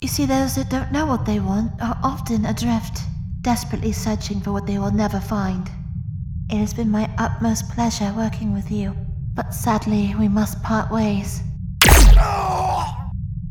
standard british | natural